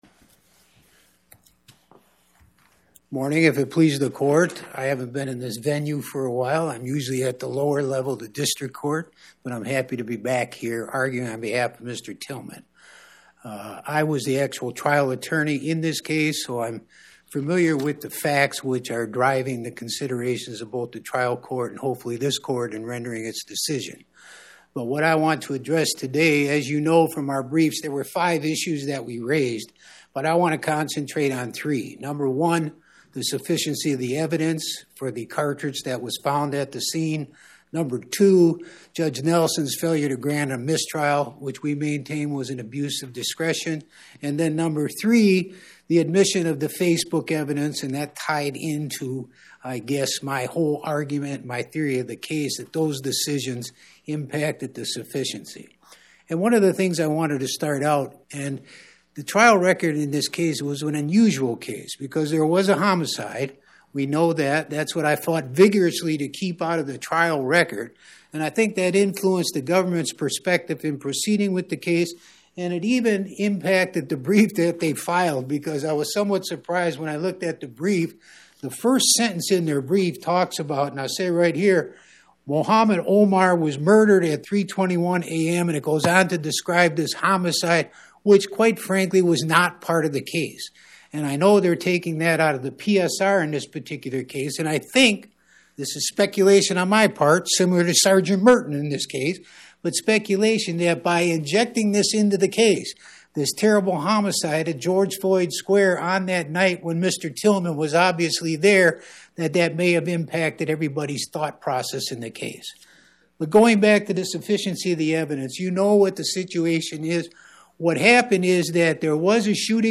Oral argument argued before the Eighth Circuit U.S. Court of Appeals on or about 10/24/2025